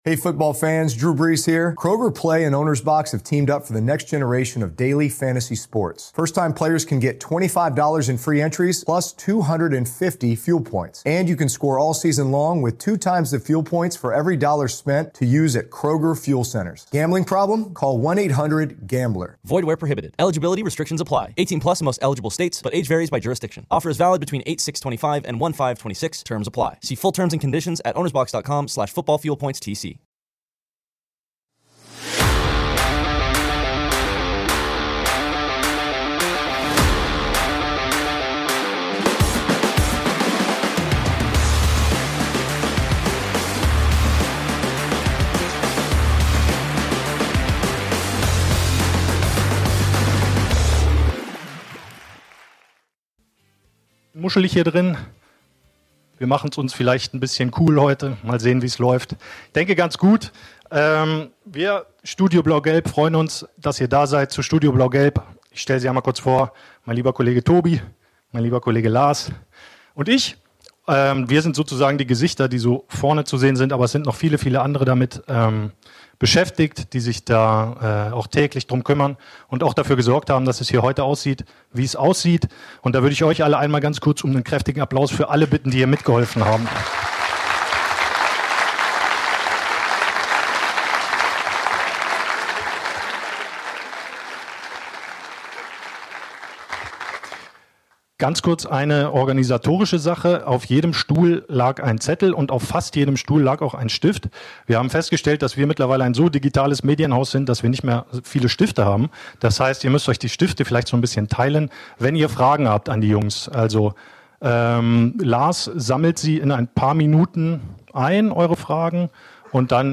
Beschreibung vor 8 Monaten Der Eintracht-Braunschweig-Podcast „Studio Blau-Gelb“ hat sich zum zweiten Mal auf die ganz große Bühne gewagt. Am 14. August empfingen die Sportredakteure hochkarätige Gäste des Fußball-Zweitligisten auf dem Podium im FORUM Medienhaus.